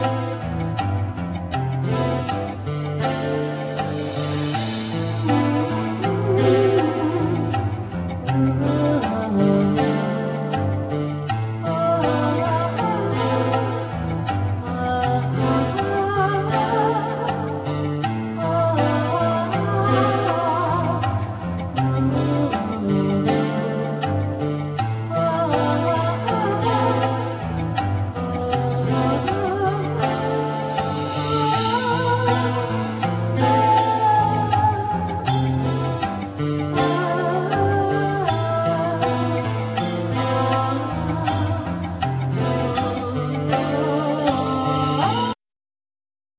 Guitar,Keyboard,Sequencer
Violin,Viola
Guitar,E-Bow
Drums,Percussion
Clarinett
Vocal
Double Bass
Cello
Flute